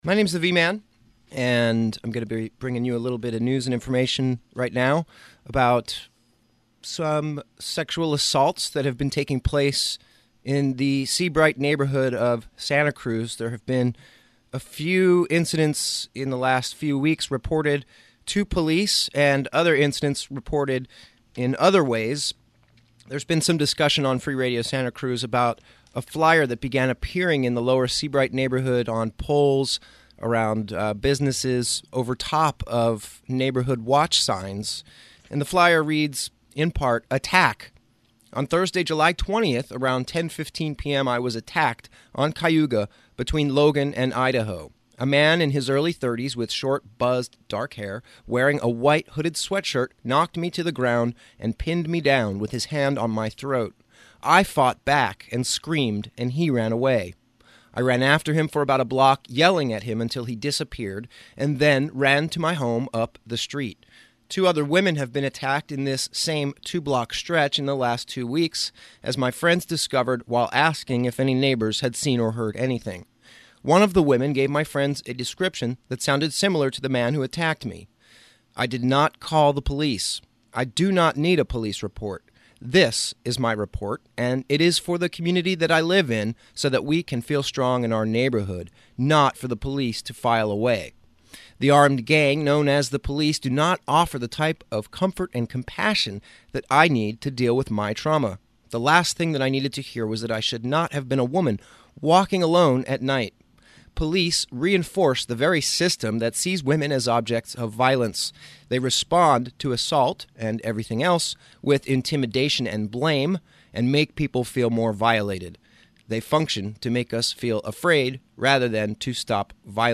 Audio and analysis of an August 3 meeting with SCPD and Seabright residents, to address a recent string of violent, sexual assaults in the neighborhood. At the meeting, police announced the arrest of a suspect in one of the sexual assaults.